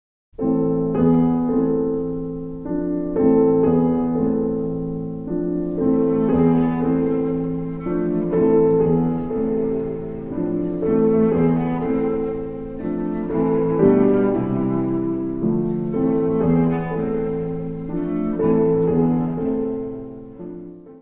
violoncelo
Music Category/Genre:  Classical Music